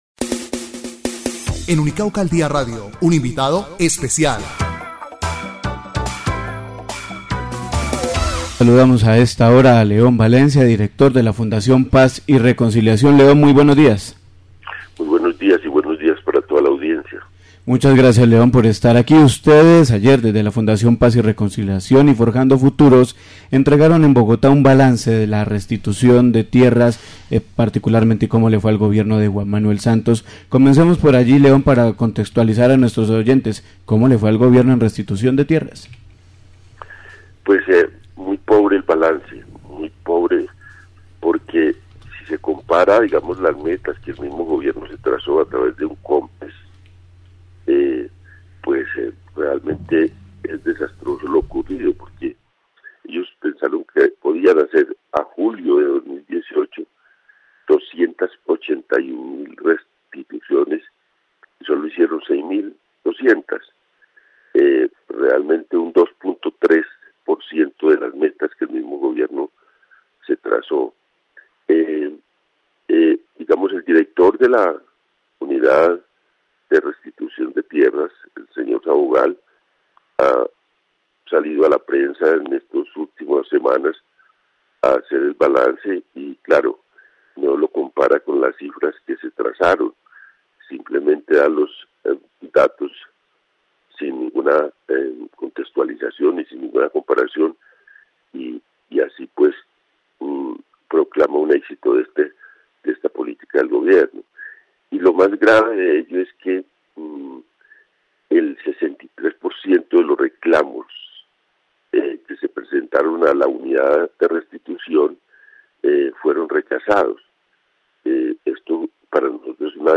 Ver más >> Entrevista a León Valencia